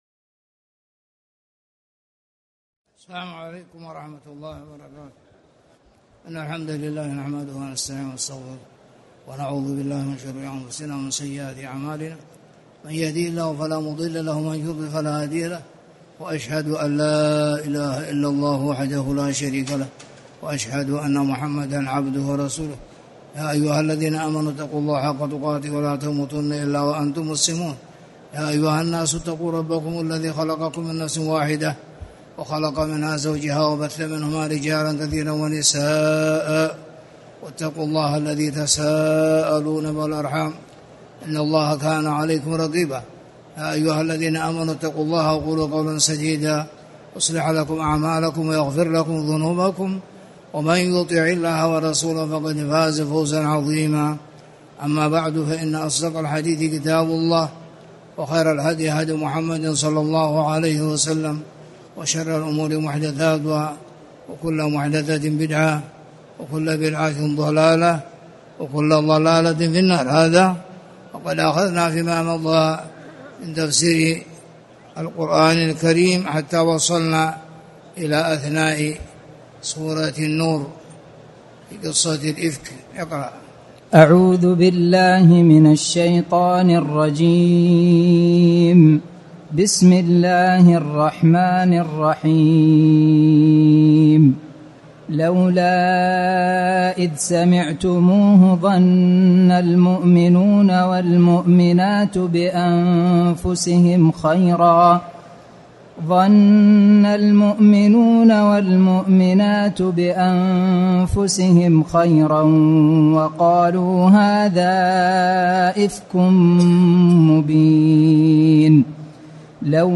تاريخ النشر ١١ ذو القعدة ١٤٣٨ هـ المكان: المسجد الحرام الشيخ